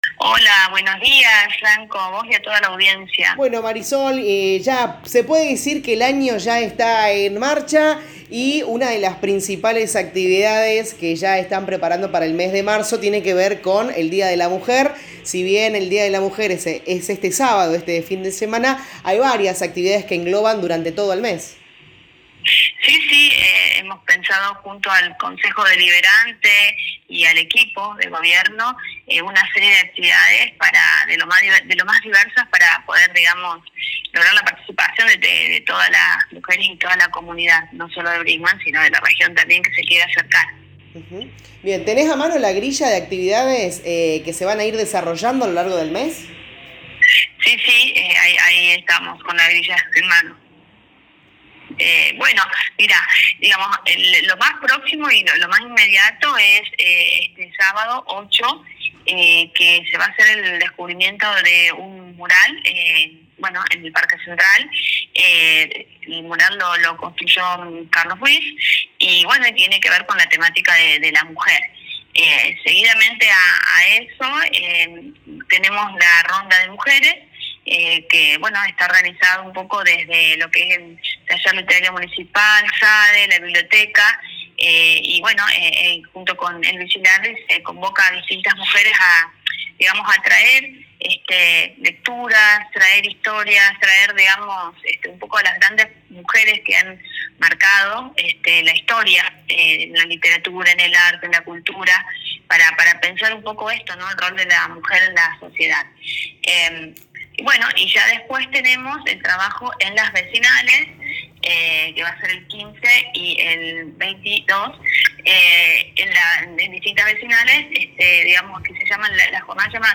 La secretaria de educación Marisol Núñez dialogó con LA RADIO 102.9 sobre las actividades previstas para todo el mes.